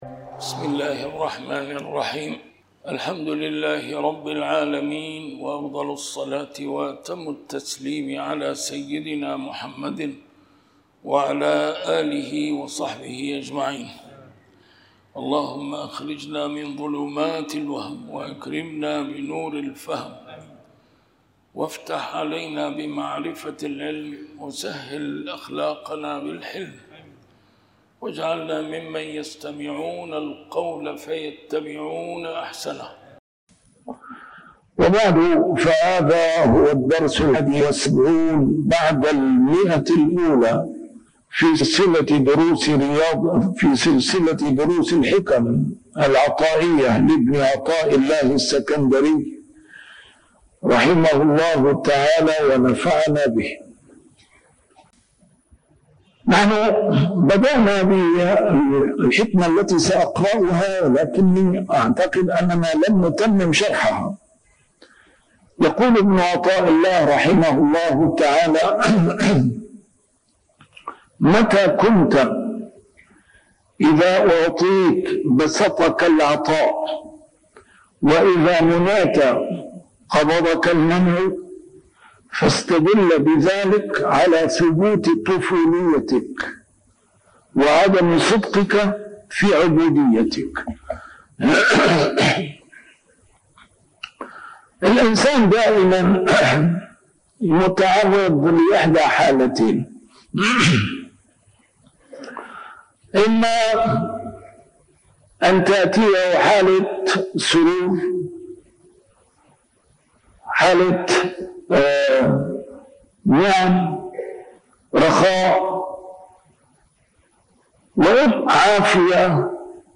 A MARTYR SCHOLAR: IMAM MUHAMMAD SAEED RAMADAN AL-BOUTI - الدروس العلمية - شرح الحكم العطائية - الدرس رقم 171 شرح الحكمة 147+148